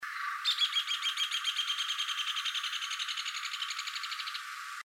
Rufous Hornero (Furnarius rufus)
Life Stage: Adult
Location or protected area: Parque 3 de Febrero
Condition: Wild
Certainty: Recorded vocal